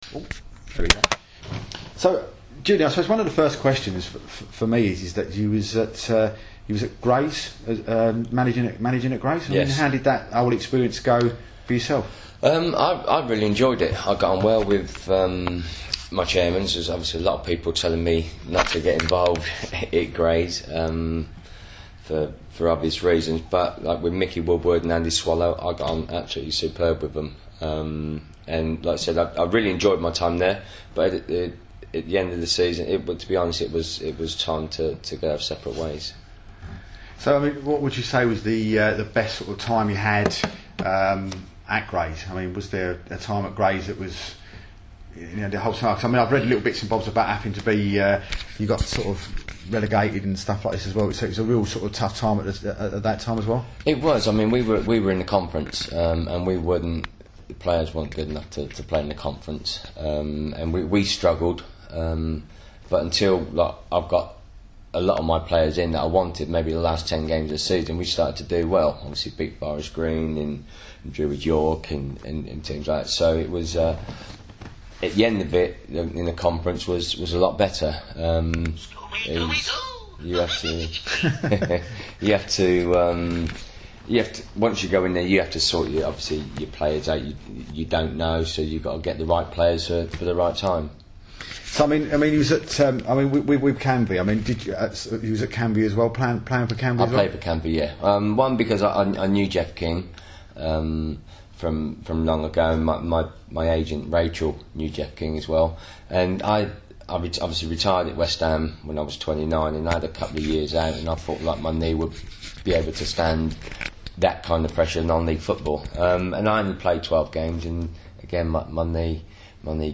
Chatting with West Ham United Liverpool And England Legend Julian Dicks